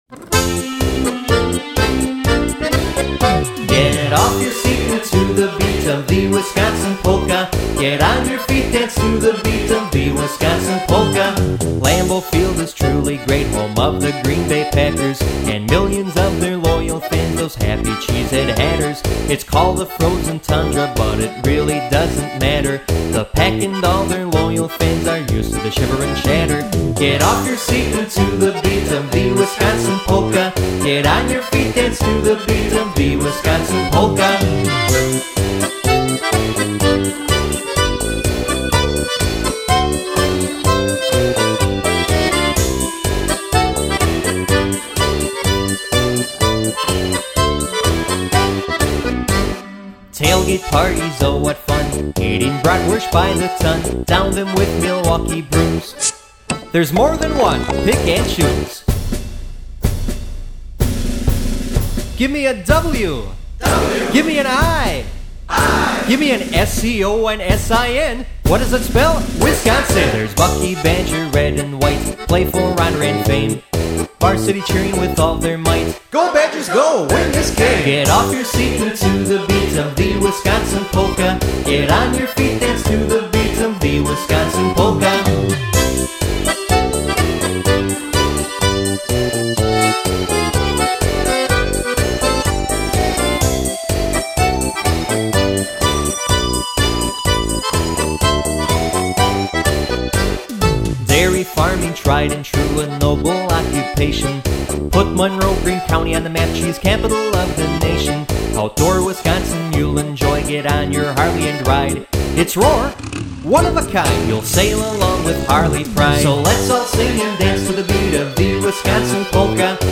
A Milwaukee, Wisconsin Polka Band